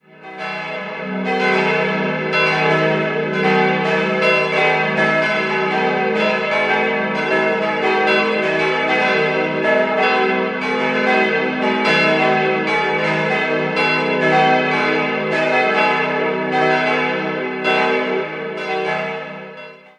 Idealquartett: e'-g'-a'-c'' Die Glocken wurden im Jahr 1978 von der Heidelberger Glockengießerei hergestellt.